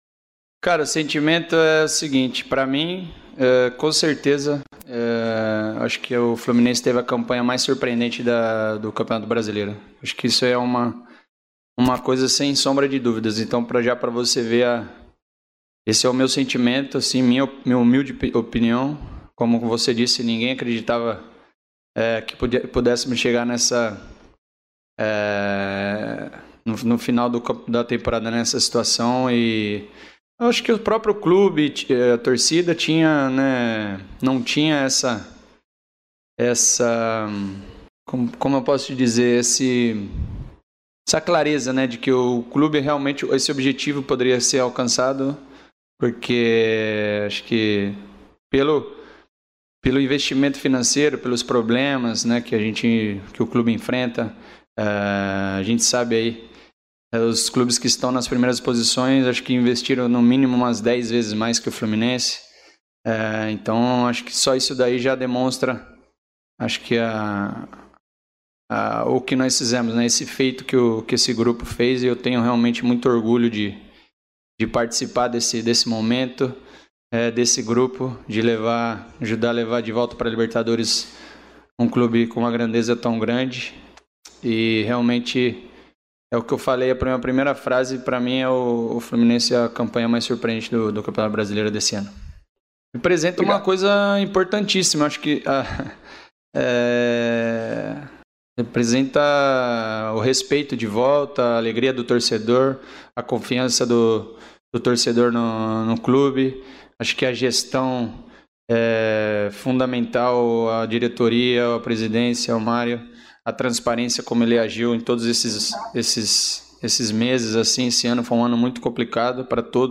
O meia Nenê foi o escolhido nesta quinta-feira (18.02) para a entrevista coletiva no CT Carlos Castilho. O meia falou sobre vários temas, entre eles, a surpreendente campanha do Fluminense no Campeonato Brasileiro e sobre a sua atual performance.
Nenê coletiva 1